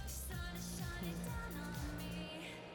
A kérdéses résznél éppen felkonferál valakit a bemondó, amikor a zene hátterében egy másik hang is hallható. Vannak, akik szerint csak egy technikai zörej, vagy a vendégek bőrfotelen való mozgolódása adhatta ki a hangot, de volt, aki úgy fogalmazott, hogy:
fing-hang.wav